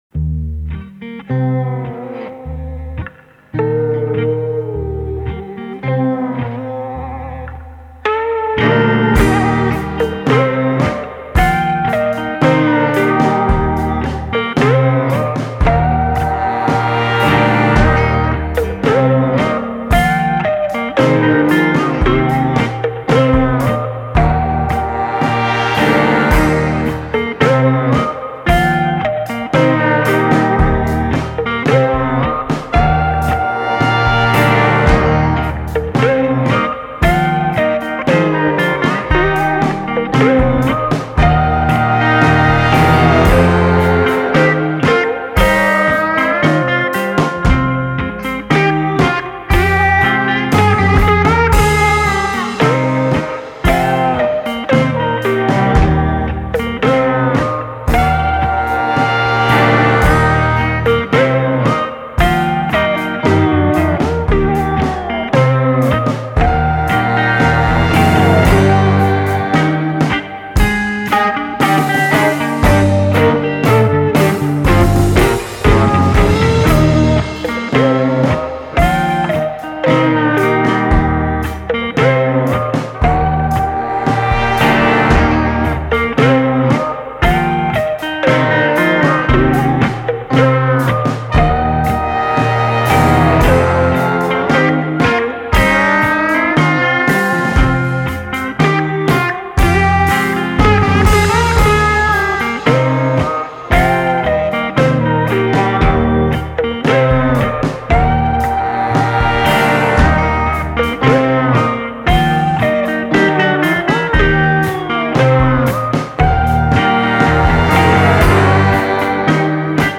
instrumental post rock old time